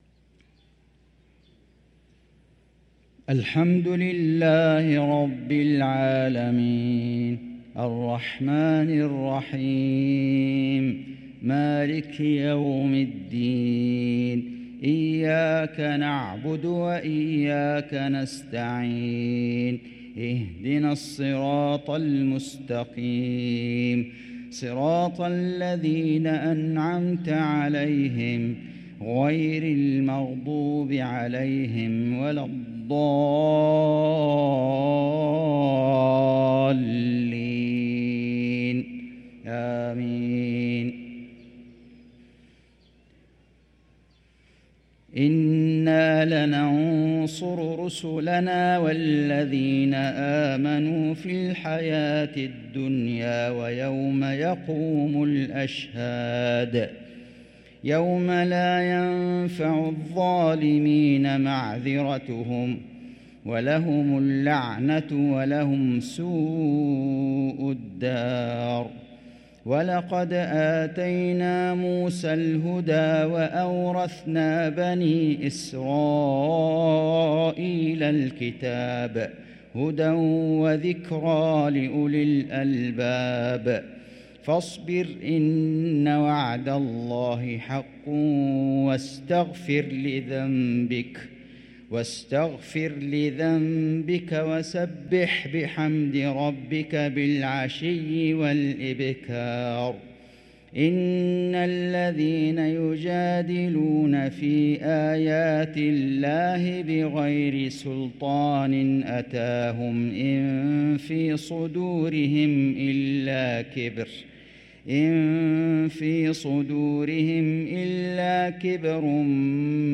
صلاة المغرب للقارئ فيصل غزاوي 23 ربيع الأول 1445 هـ
تِلَاوَات الْحَرَمَيْن .